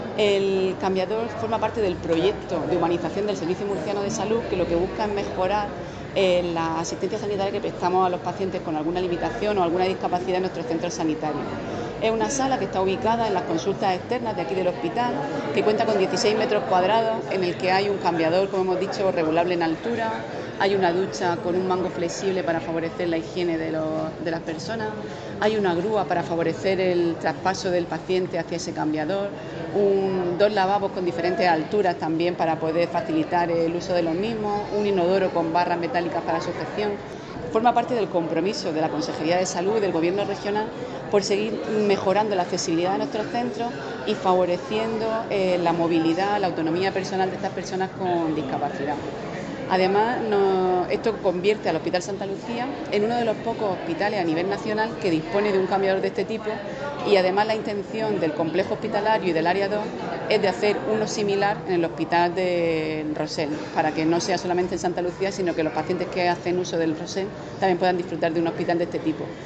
Declaraciones de la gerente del SMS, Isabel Ayala, en su visita al hospital Santa Lucía, donde se ha instalado un cambiador adaptado.[mp3]